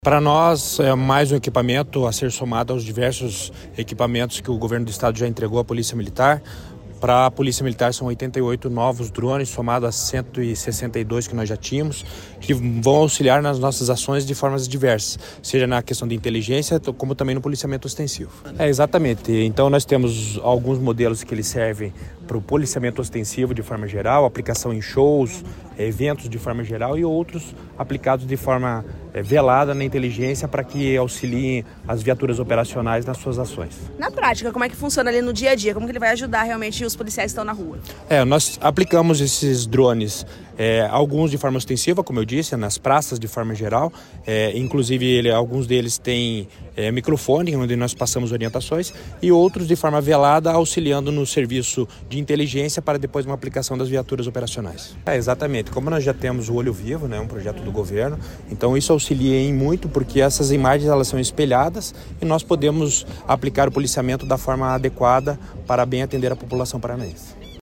Sonora do comandante-geral da PMPR, coronel Jefferson Silva, sobre a entrega de 243 drones para monitoramento aéreo das forças de segurança do Paraná | Governo do Estado do Paraná